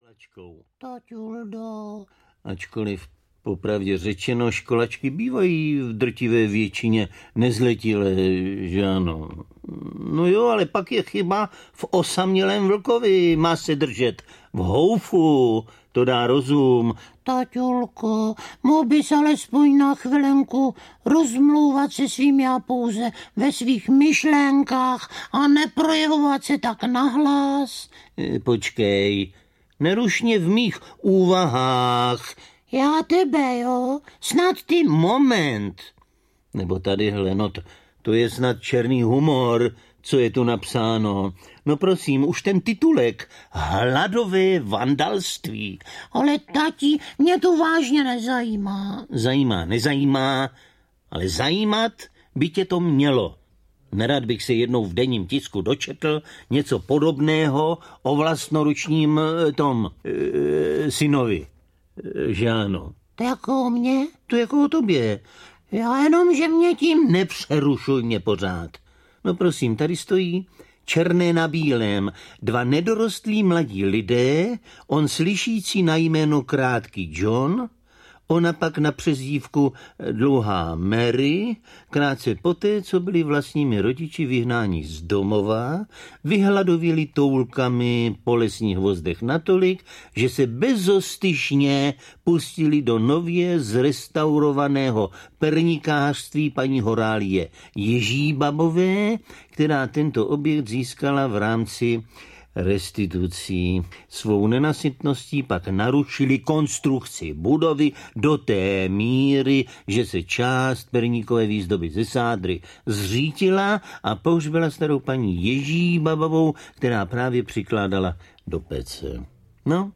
Hurvínek na stopě audiokniha
Ukázka z knihy
V rolích Máničky a paní Kateřiny samozřejmě Helena Štáchová.
hurvinek-na-stope-audiokniha